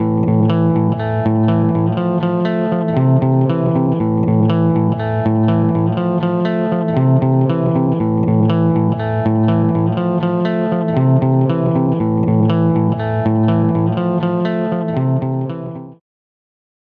Guitar Dry
Sonicircuit-Stereo-Flanger-08.Psychoacoustic-Tube-Guitar-Dry.mp3